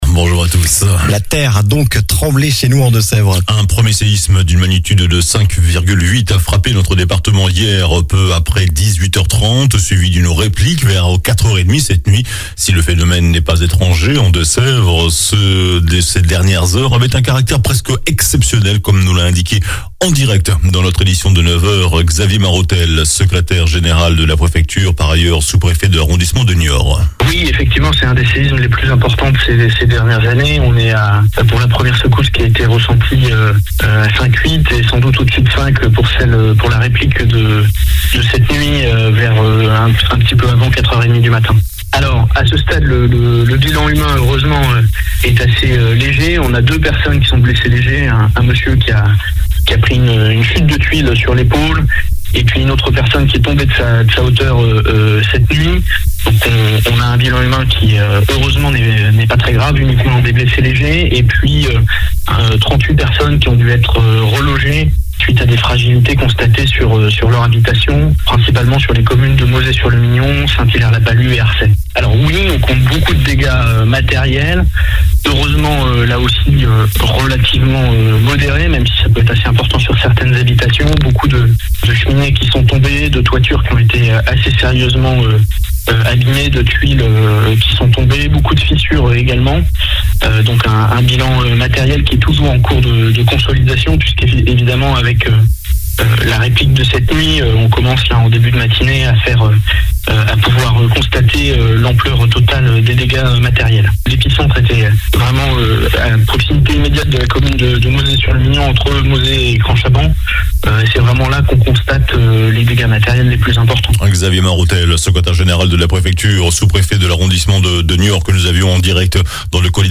JOURNAL DU SAMEDI 17 JUIN